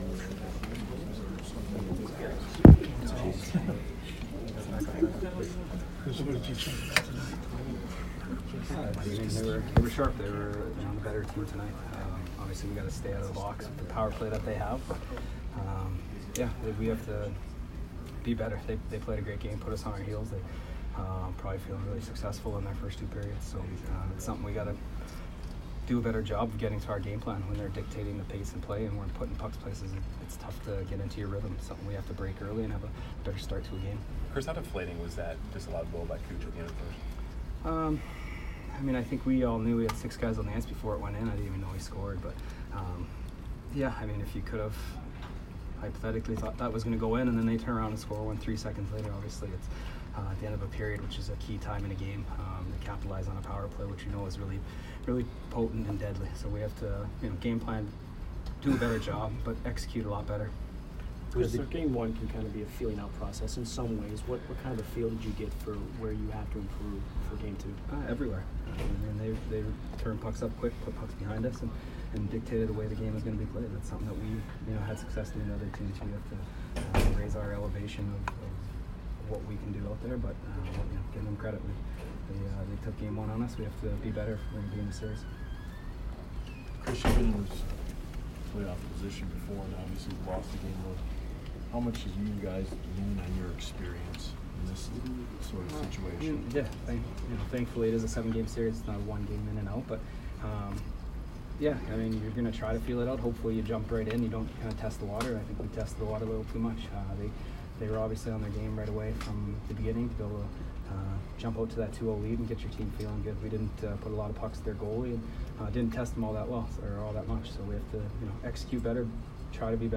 Chris Kunitz post-game 5/11